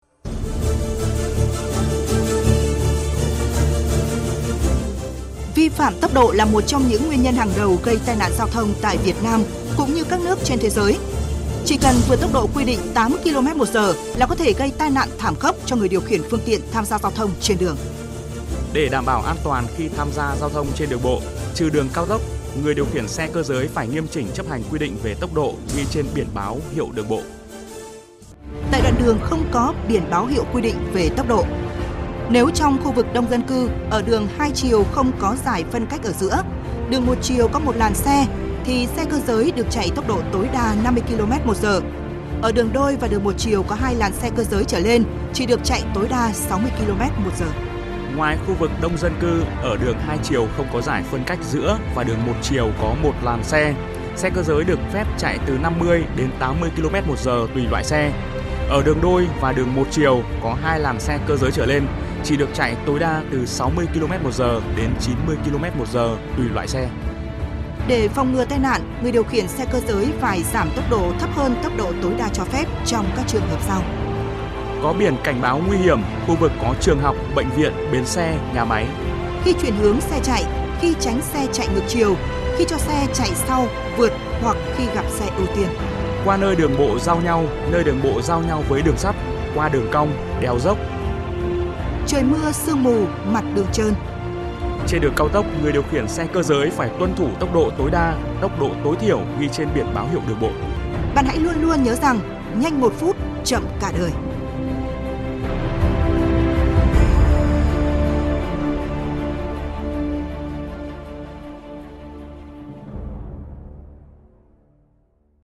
File audio Thông điệp vi phạm tốc độ: